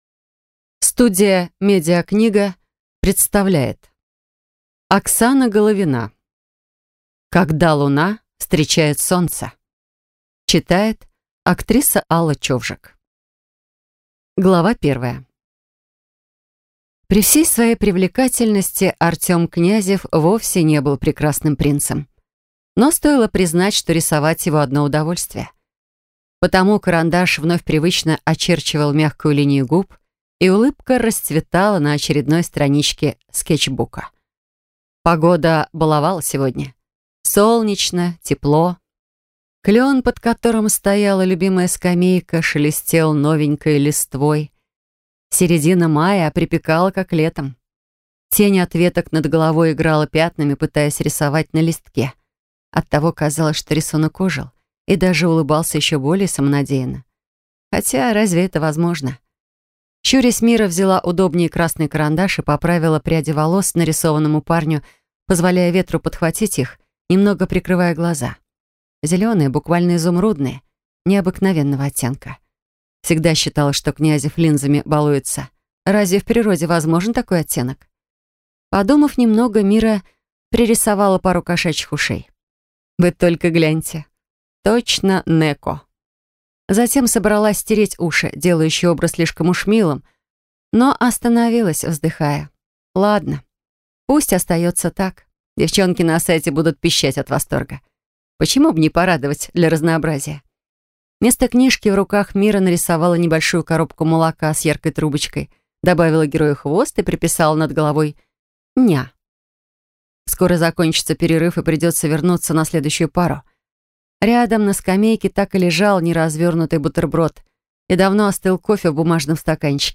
Аудиокнига Когда Луна встречает Солнце | Библиотека аудиокниг